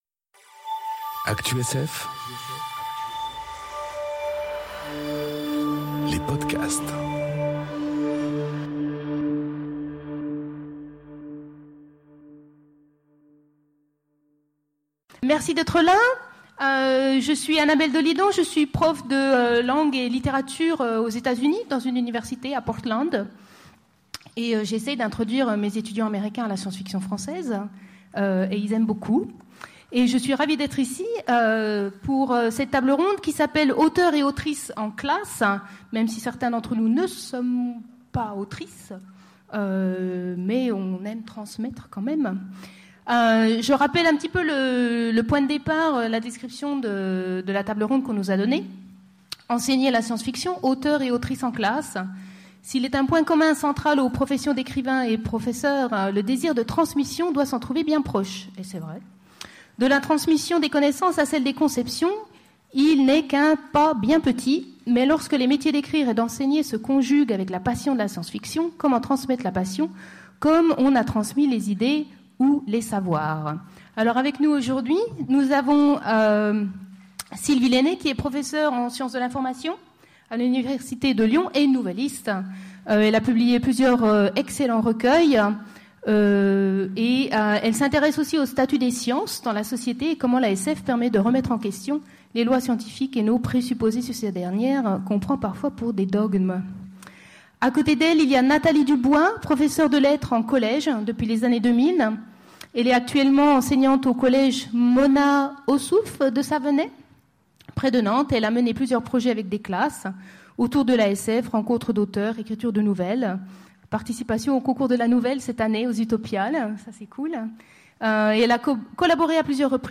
Conférence Enseigner la science-fiction : auteurs et autrices en classe enregistrée aux Utopiales 2018